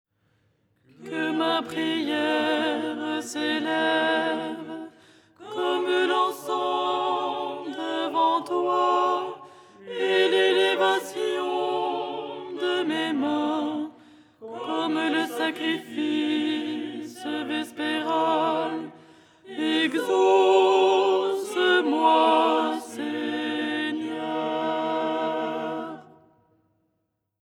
Alto
ton6-02-alto.mp3